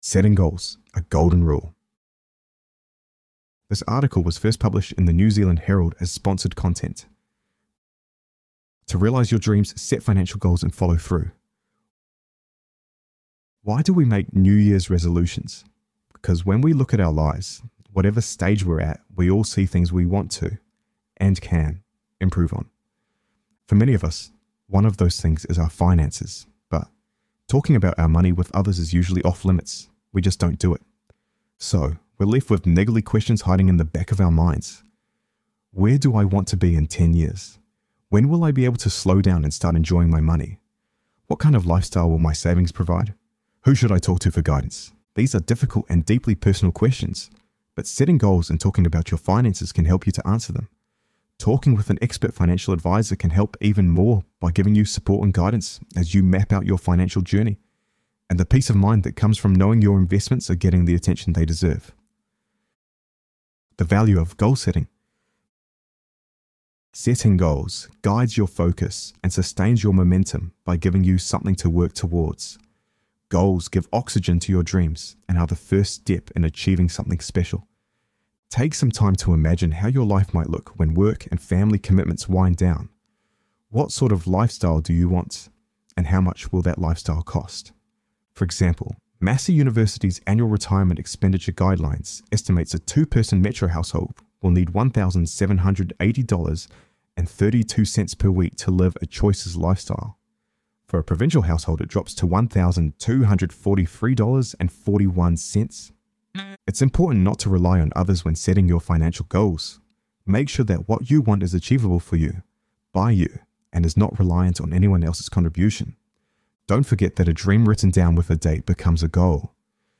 This content features an AI-generated voice for narration purposes.